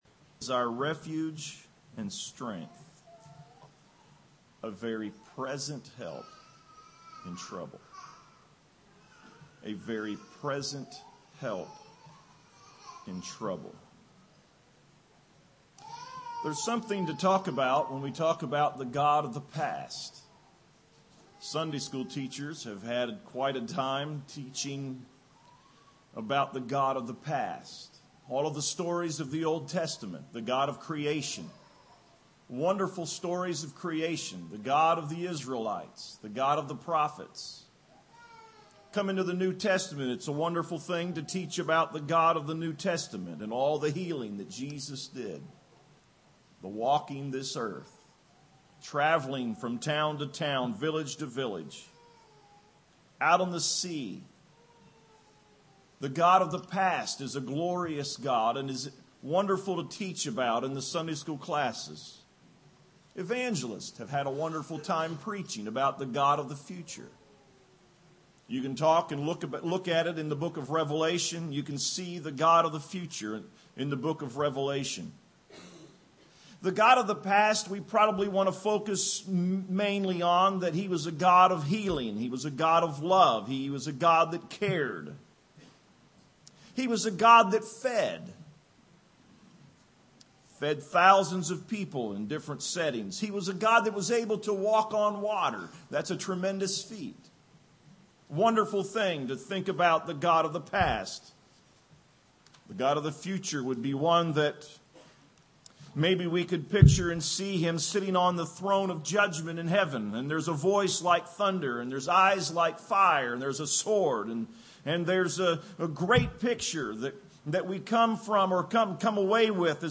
Audio A sermon